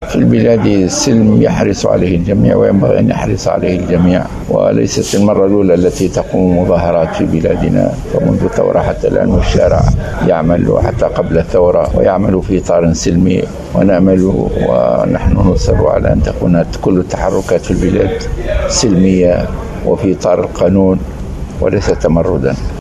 Le chef d’Ennahdha, Rached Ghannouchi, a assuré, dans une déclaration relayée ce soir du lundi 15 février 2021, par Mosaïque FM, que l’appel de son parti à une marche de soutien au gouvernement, s’inscrit dans un cadre pacifique et que la manifestation prévue ne sortira pas du cadre de la loi.